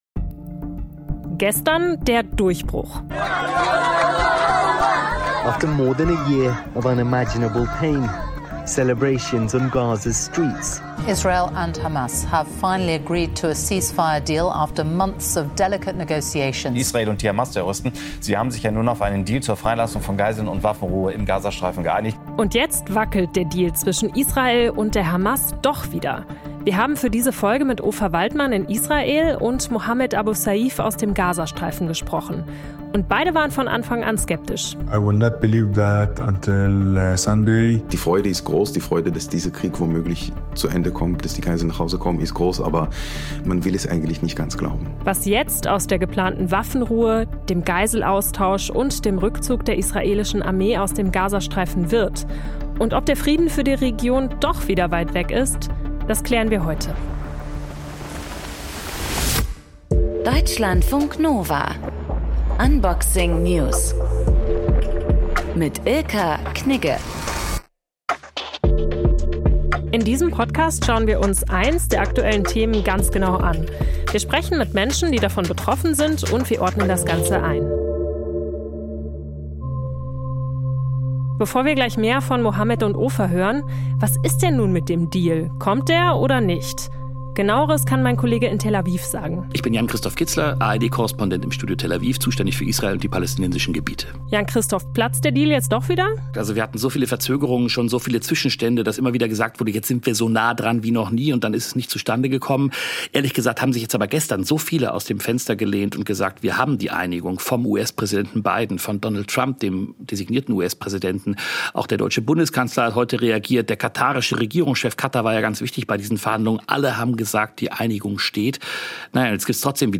Das Interview im Deutschlandfunk Kultur greift kulturelle und politische Trends ebenso auf wie...